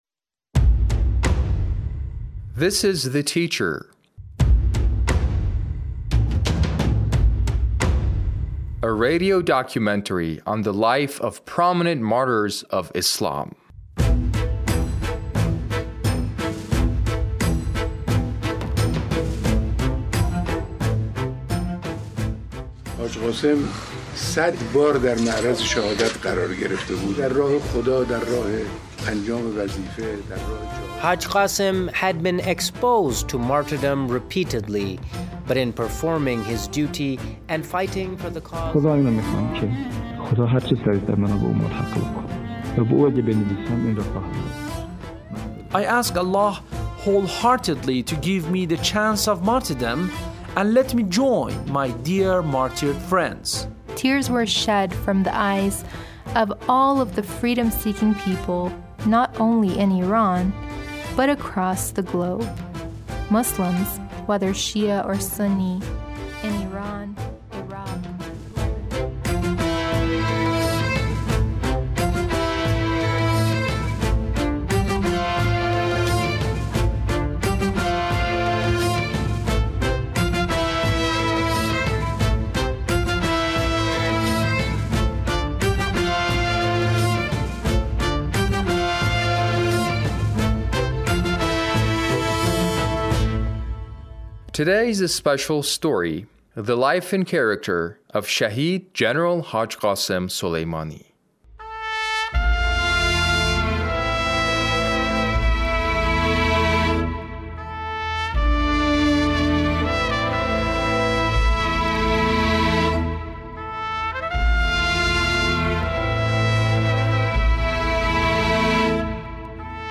A radio documentary on the life of Shahid Qasem Suleimani - Part 1